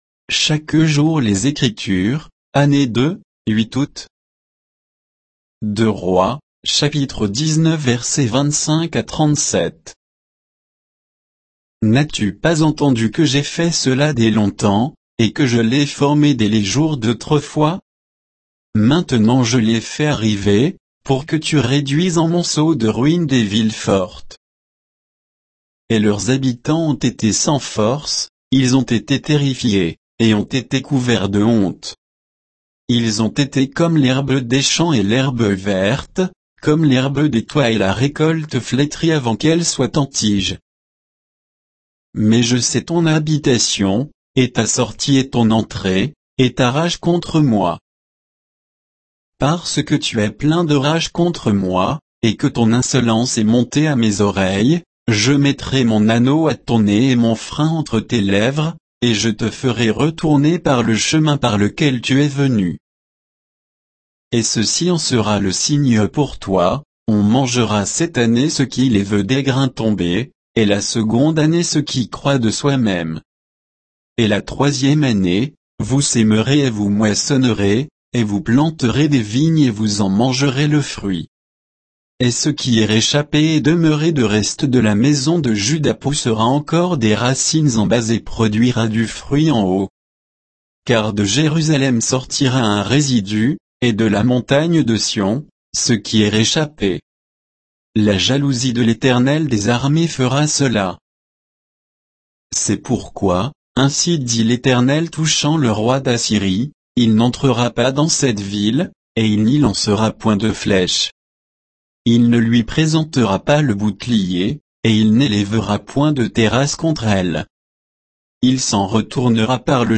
Méditation quoditienne de Chaque jour les Écritures sur 2 Rois 19, 25 à 37